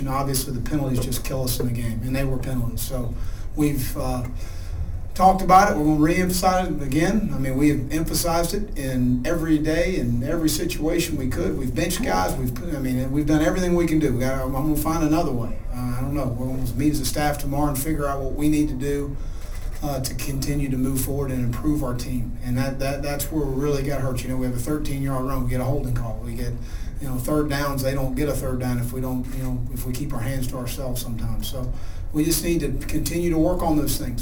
Florida was flagged for 10 penalties resulting in 79 yards, and Gator coach Will Muschamp, excited as he was over the victory, wasn’t happy about the lack of discipline in his postgame press conference.